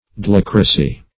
Search Result for " doulocracy" : The Collaborative International Dictionary of English v.0.48: Doulocracy \Dou*loc"ra*cy\, n. [Gr. doy^los slave + kratei^n to rule.]